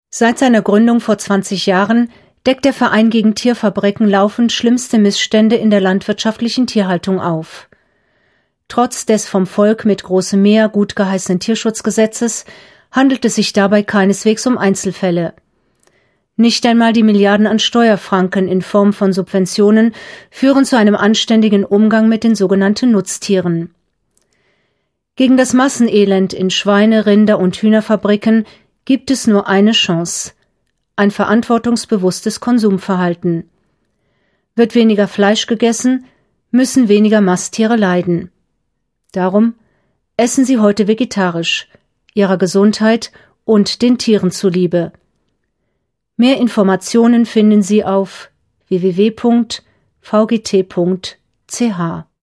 Radio-Spot zum 20-jährigen Jubiläum des VgT